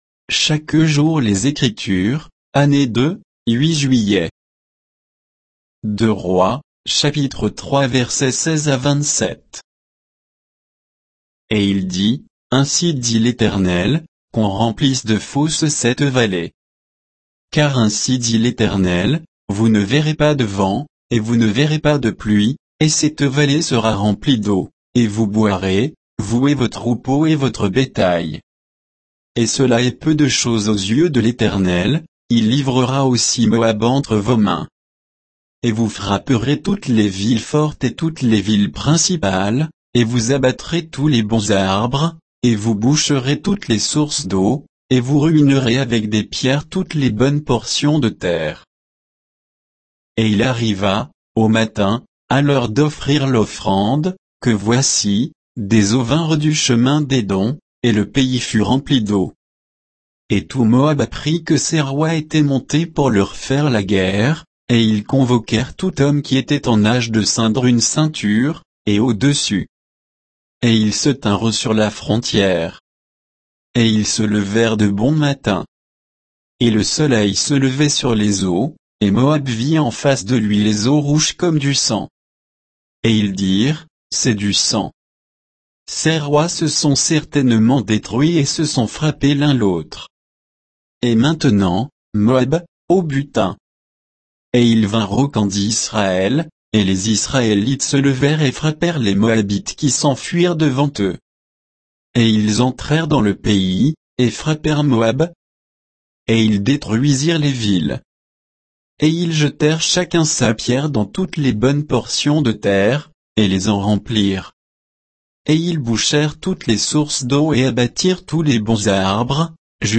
Méditation quoditienne de Chaque jour les Écritures sur 2 Rois 3